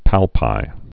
(pălpī)